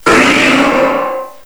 cries